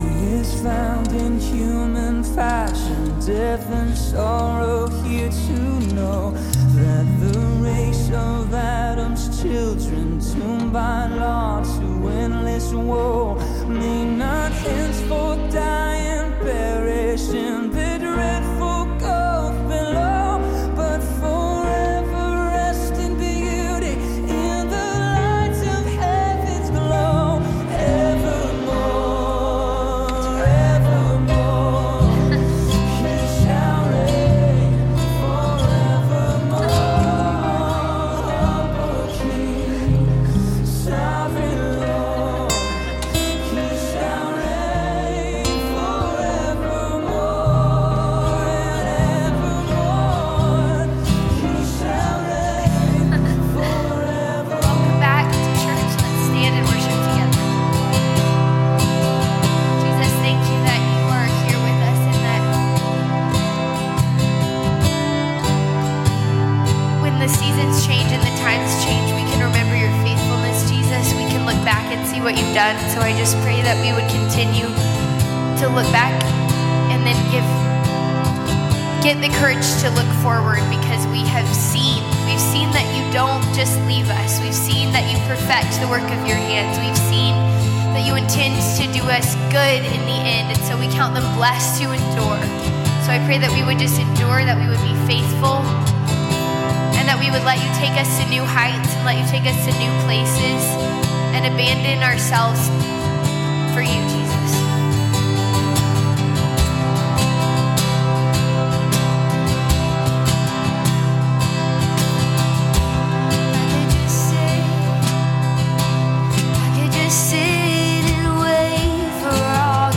Calvary Knoxville Sunday PM Live!